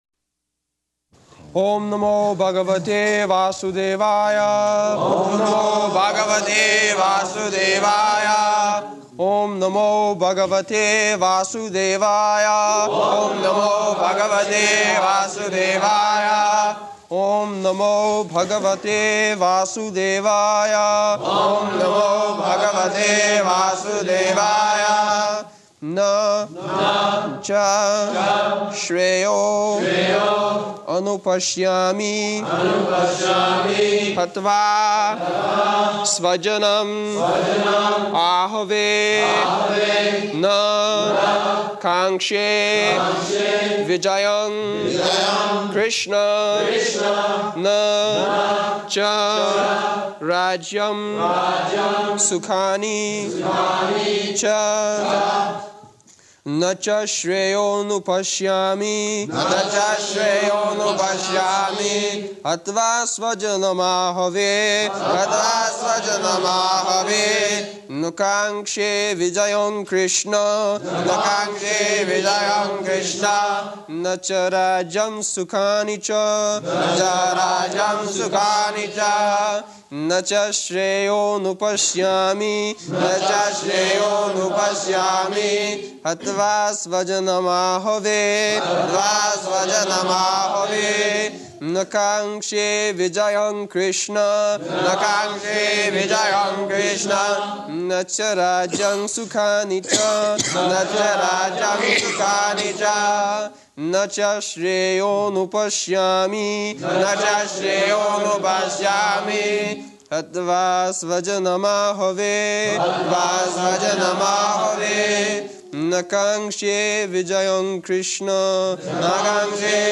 July 24th 1973 Location: London Audio file
[leads chanting of verse] [Prabhupāda and devotees repeat]